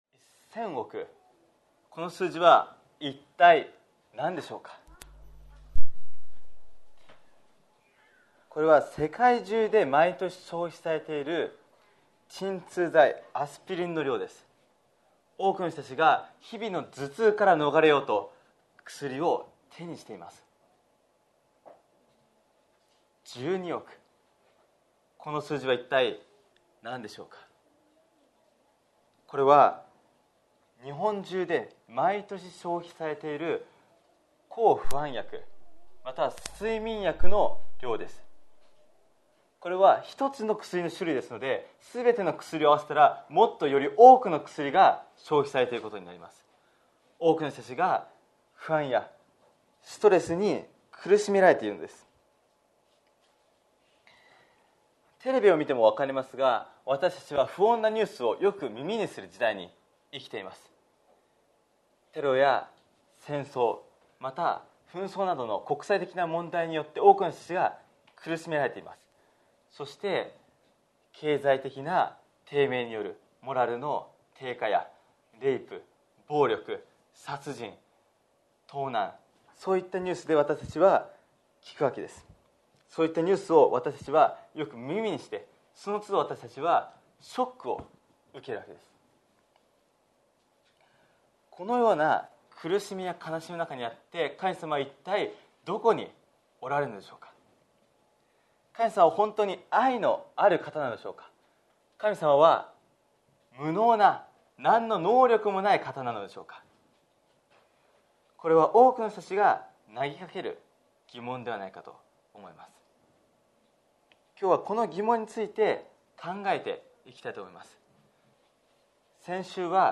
礼拝説教 , 講演会 マラナ・タ19 連続講演会 聖書から見る世界の行方 犯罪、自然災害、難病、経済問題。